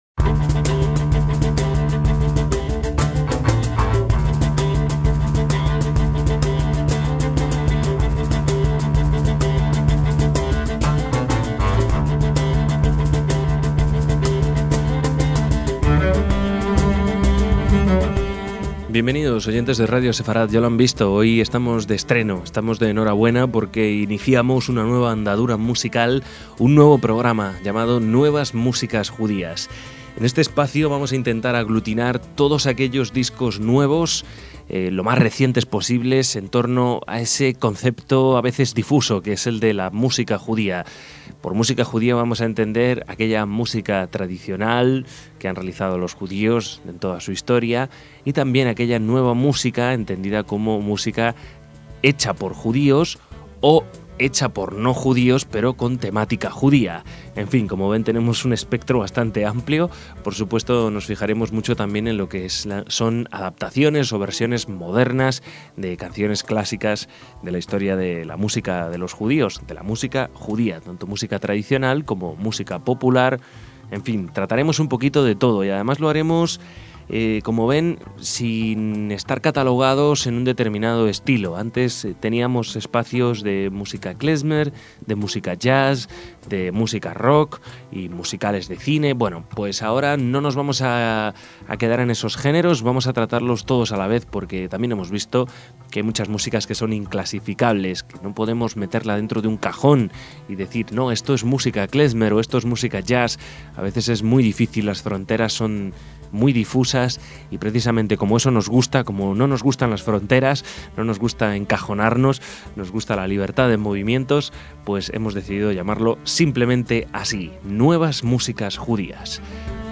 viola
chelo
contrabajo
percusiones
trasciende la tradición klezmer